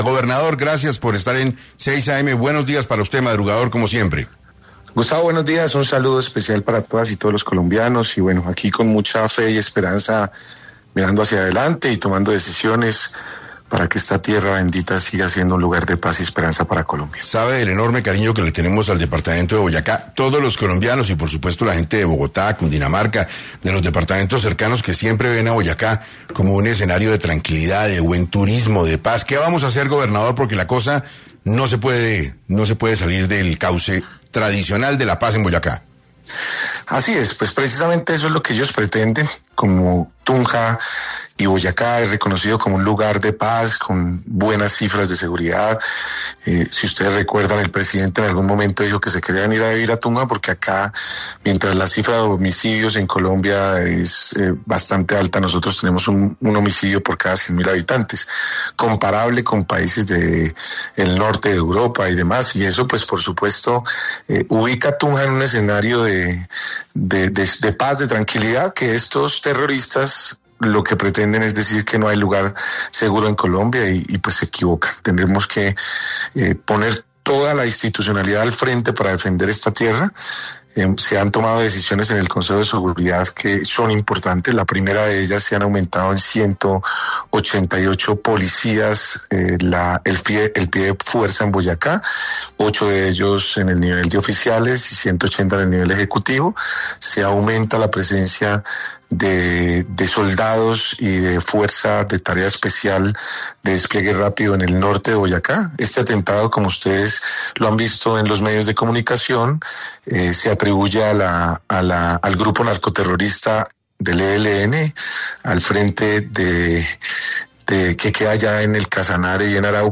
En entrevista con 6AM de Caracol Radio, el gobernador de Boyacá, Carlos Amaya, asegura que lo que quieren los terroristas es decir que no hay lugar seguro en Colombia y se equivocan. Comentó que se han tomado decisiones en el Consejo de Seguridad que son importantes: la primera de ellas es que se han aumentado en 188 policías el pie de fuerza en Boyacá y se aumenta la presencia de soldados y de fuerza de tarea especial de despliegue rápido en el norte de Boyacá.